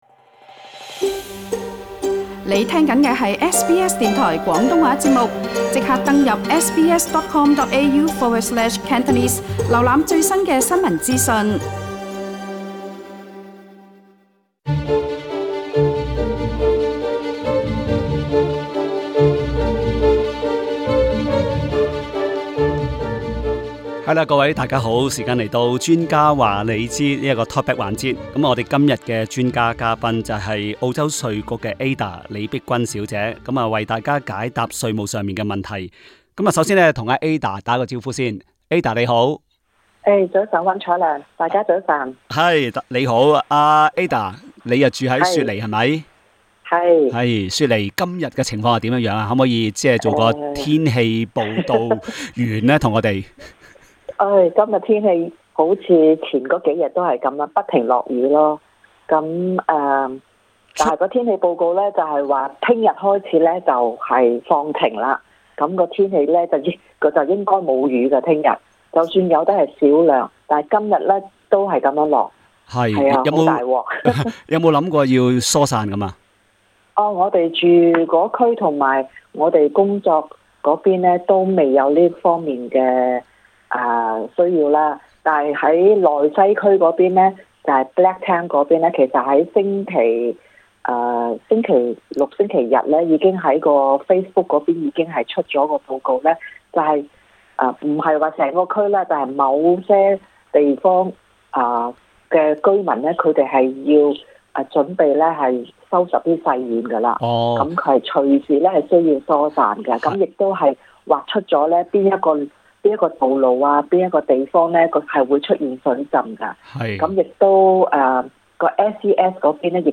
資產增值稅 (Capital Gains Tax, CGT) 一直是【專家話你知】Talkback 環節中，一個比較熱門的話題。今天好些聽眾不約而同致電查詢這方面的問題。